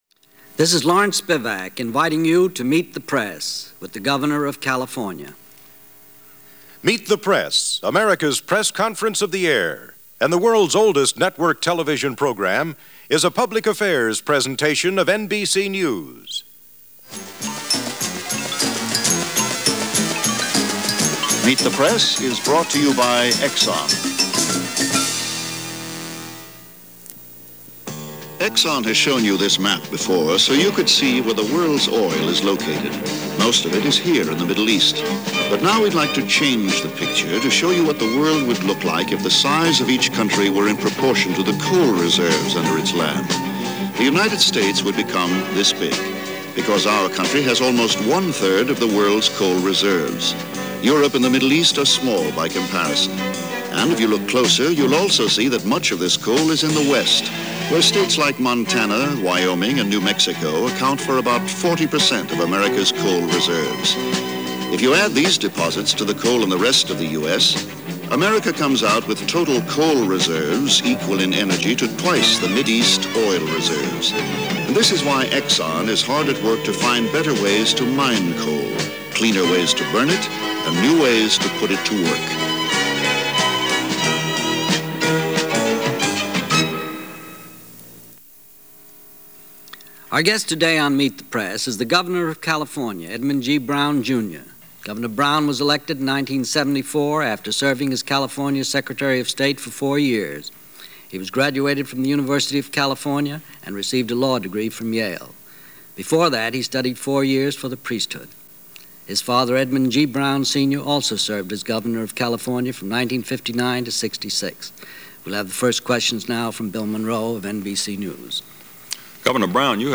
Governor Jerry Brown talks about California and where it is economically and socially - interviewed as part of Meet The Press - 1975.